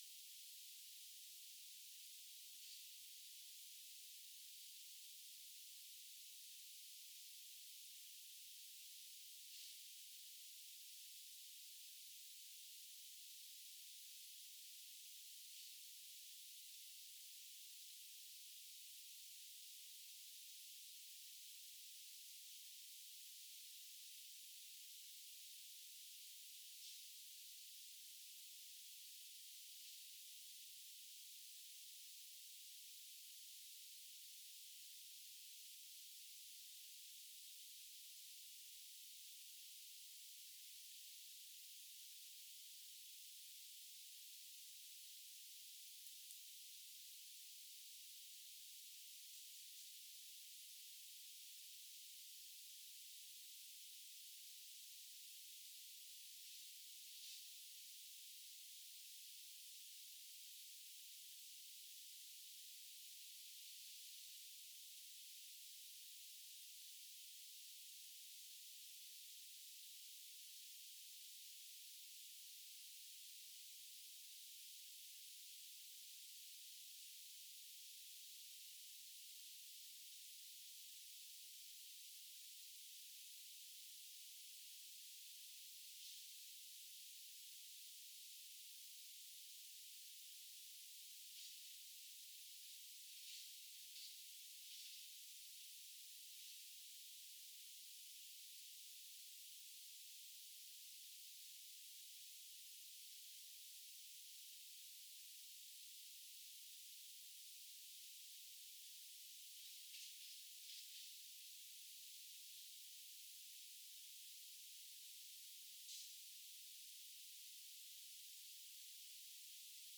Demonstration soundscapes
anthropophony